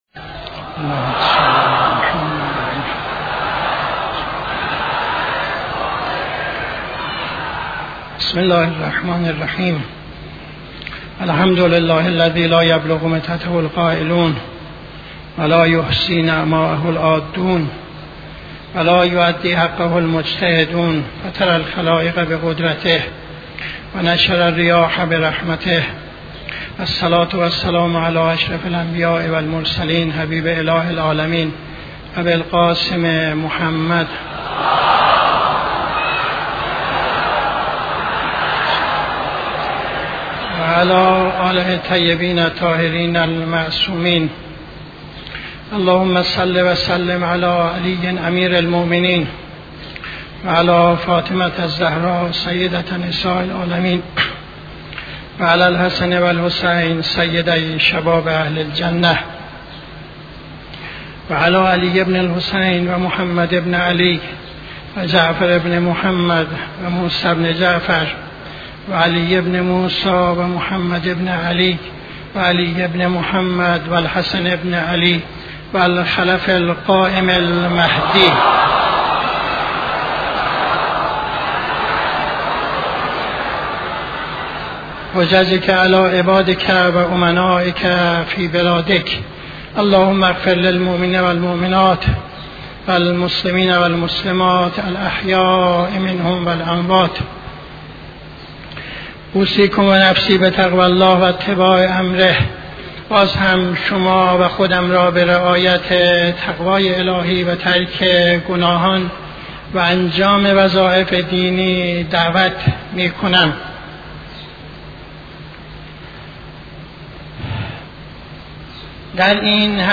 خطبه دوم نماز جمعه 26-06-74